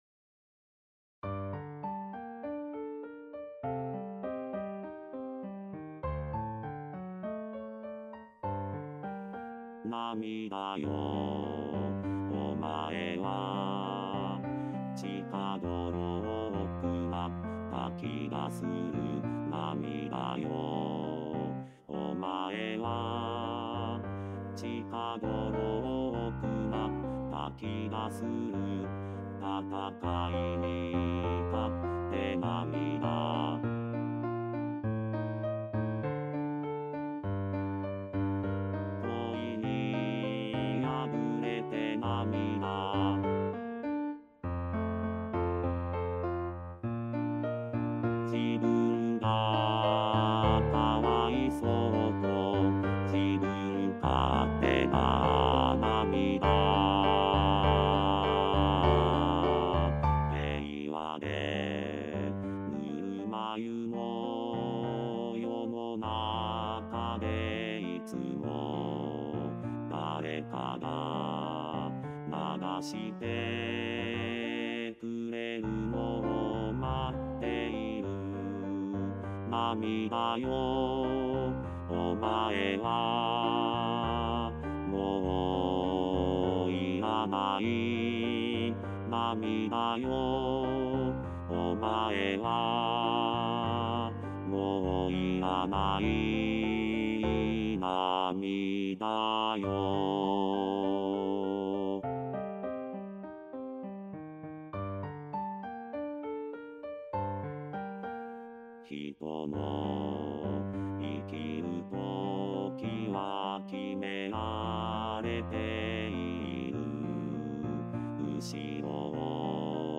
４．さらば涙よ（男声合唱）
●パート単独音源　　　■Rchソプラノ、Lch、アルト、テノール、バス
sarabanamidayo_bassueonly.mp3